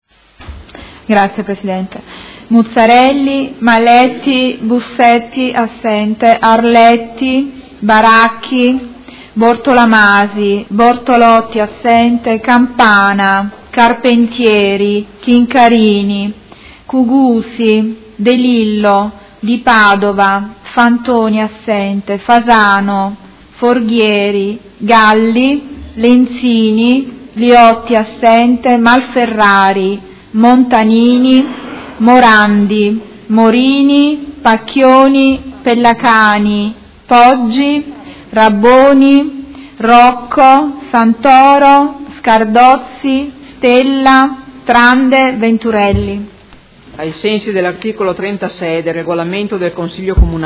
Seduta del 11/05/2017 Appello